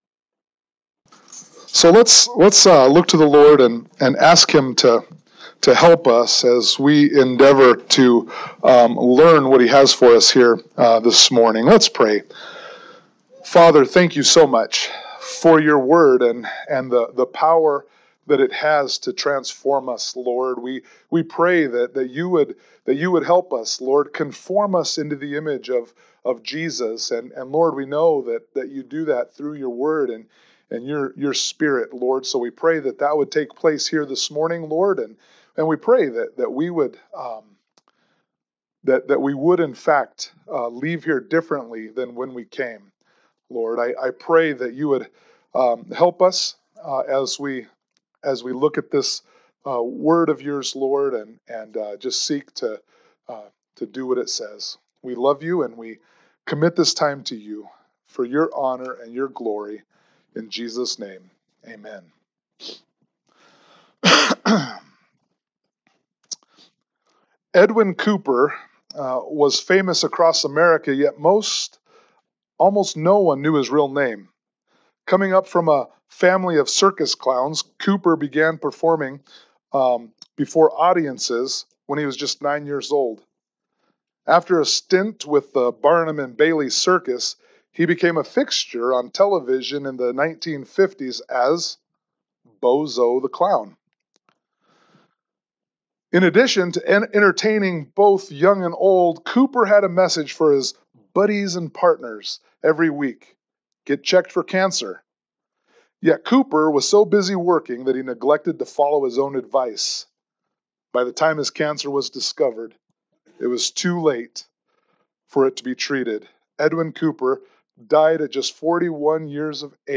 1 John 3:4-10 Service Type: Sunday Morning Worship « 1 John 3:1-3